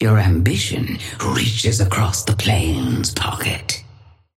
Sapphire Flame voice line - Your ambition reaches across the planes, Pocket.
Patron_female_ally_synth_start_02.mp3